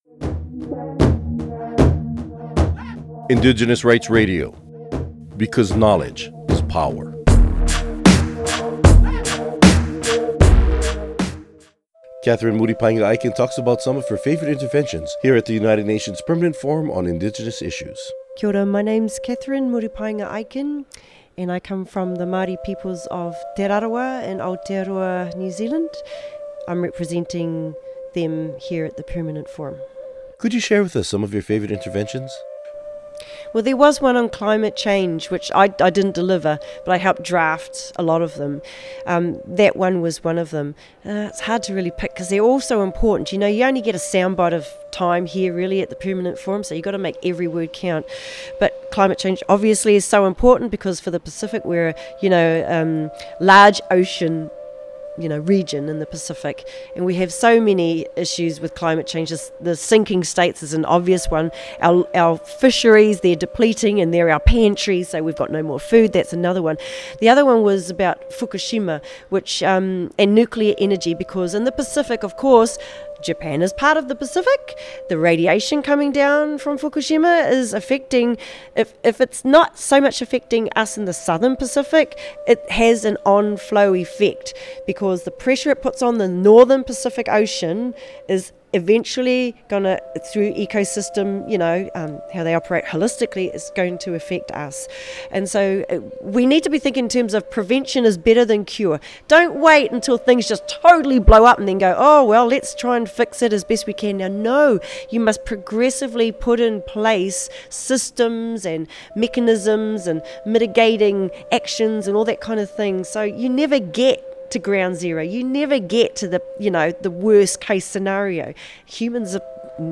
Recording Location: UNPFII 2015
Type: Interview
0kbps Stereo